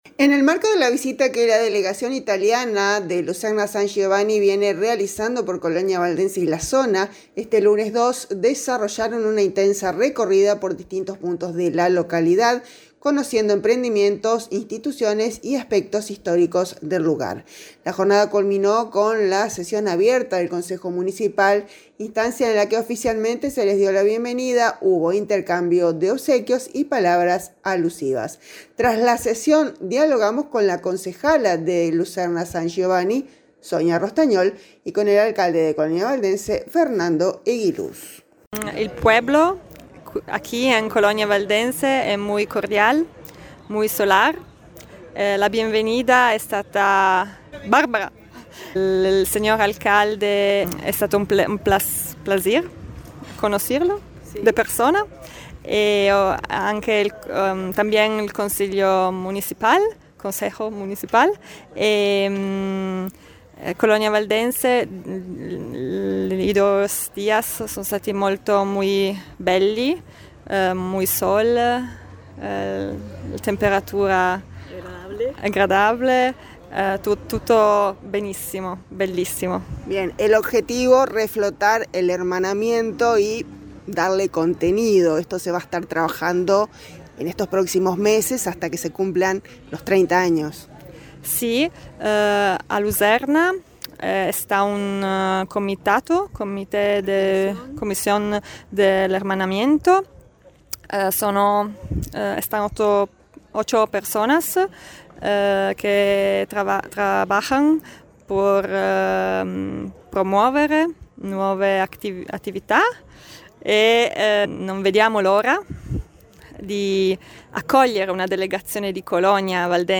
Tras la sesión, dialogamos con la concejala de Luserna San Giovanni, Sonia Rostagnol, quien destacó la cordialidad recibida y el objetivo de reactivar el hermanamiento de cara a los 30 años que se cumplirán en noviembre de 2027.
A continuación, compartimos la palabra de Sonia Rostagnol y del alcalde Fernando Eguiluz.